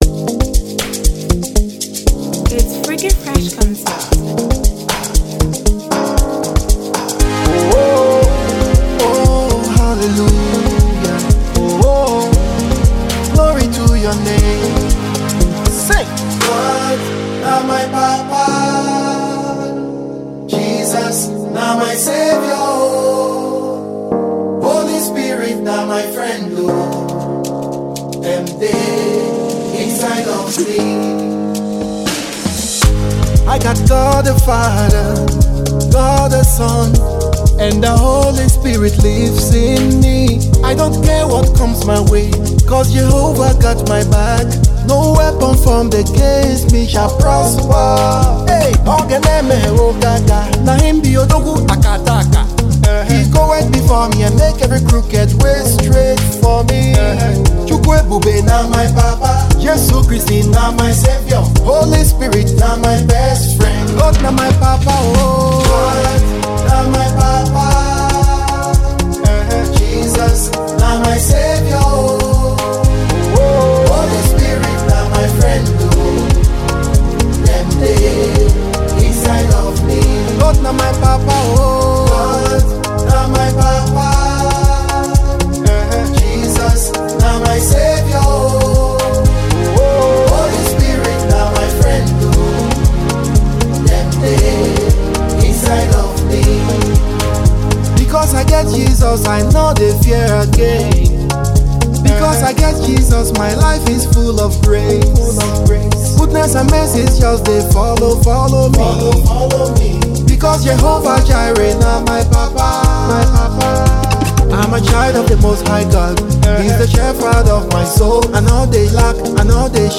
Gospel tune